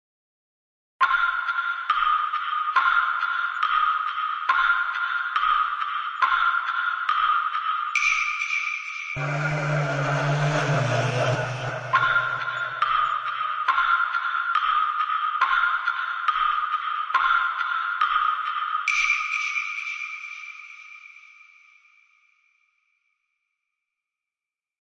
Scary Melody Sound Button - Free Download & Play